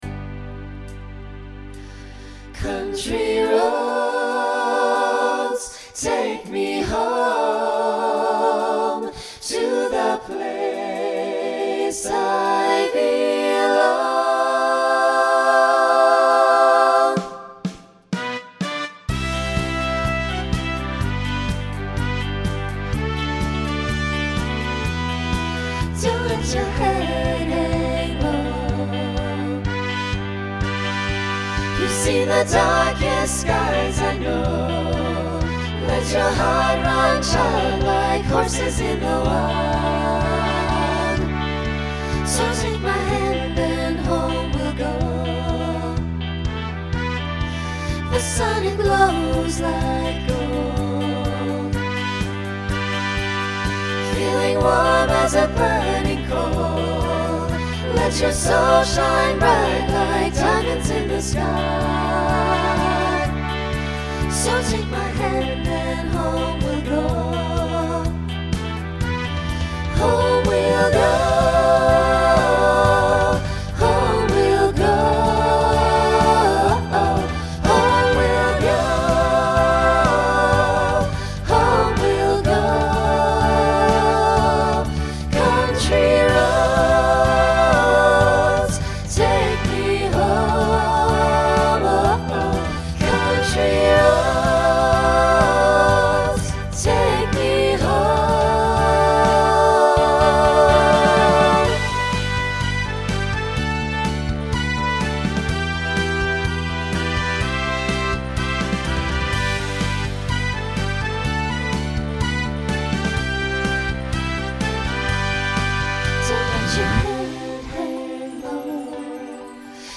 Country , Rock
Voicing SATB